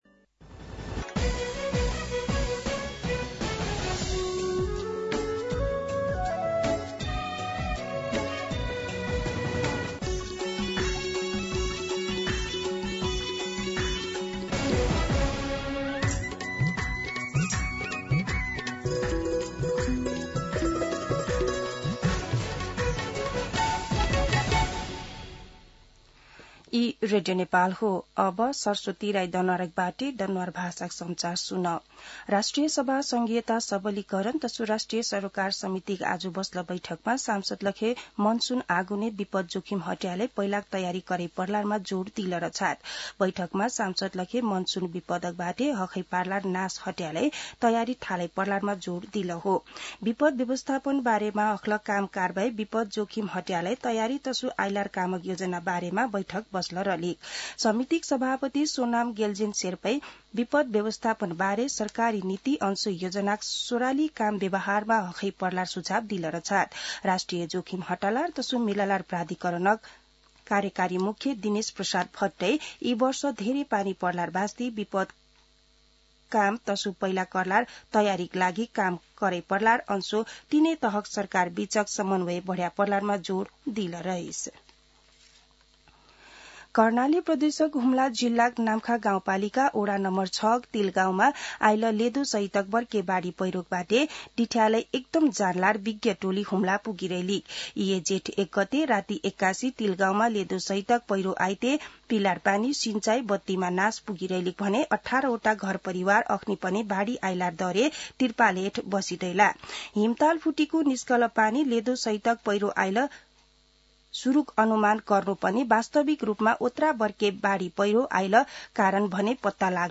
दनुवार भाषामा समाचार : ८ जेठ , २०८२